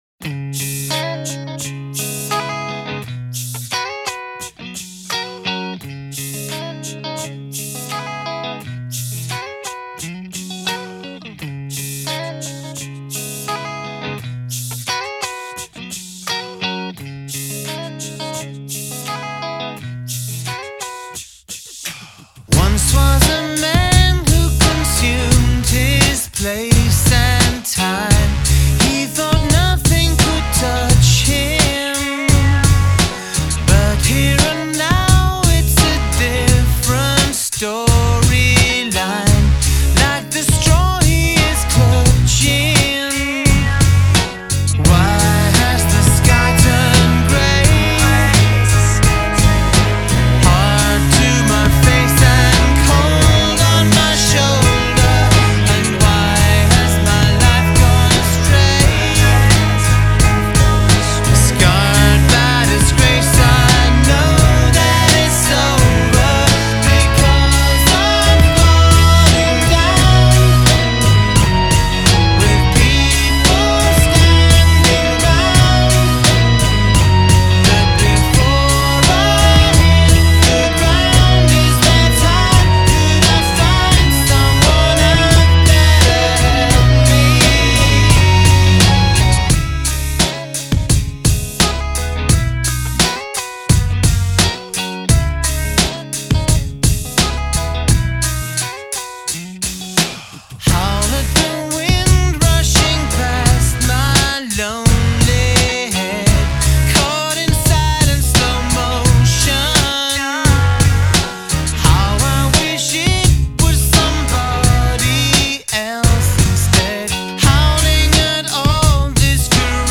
It’s ballad-y and melodic and, guess what?